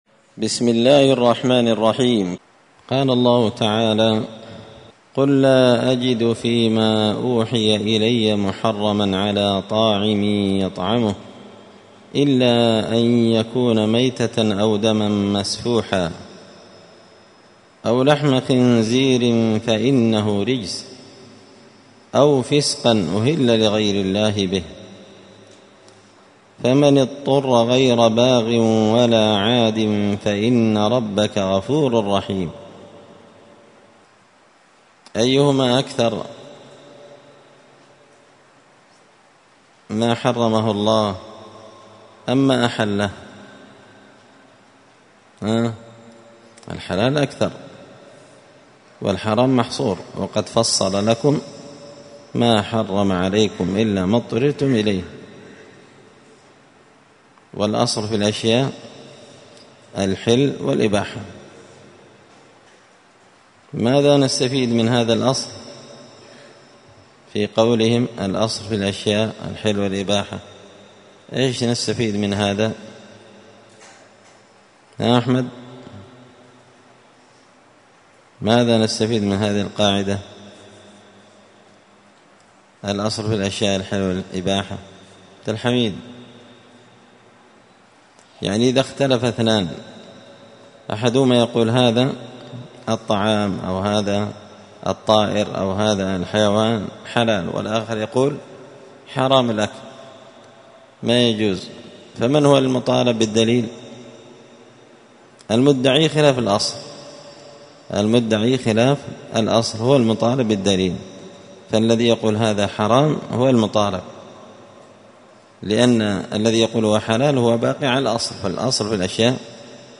الدروس اليومية
مسجد الفرقان قشن_المهرة_اليمن